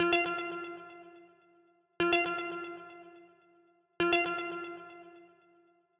alert.mp3